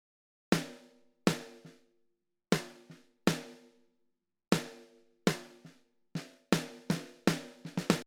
イコライジング前の音
スネアのみ
使用している音源は、Superior Drummer 2の標準キットをパラアウトで使っています。